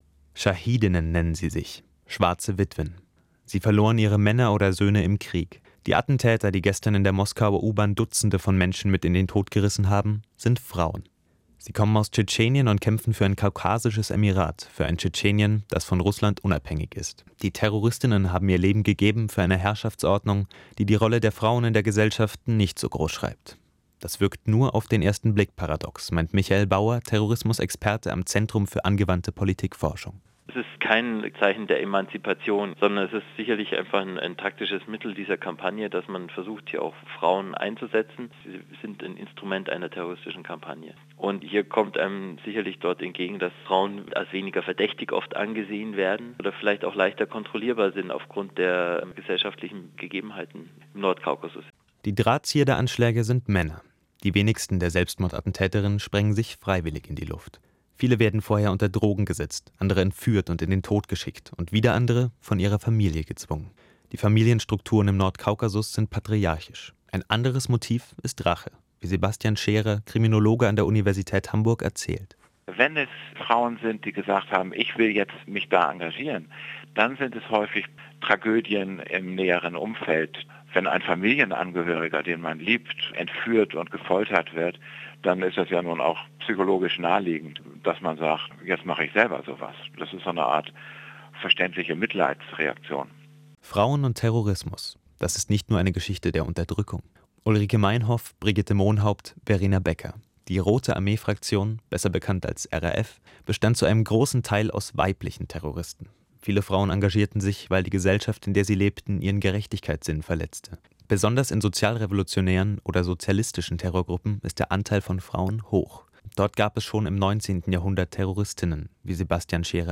Audio-Statement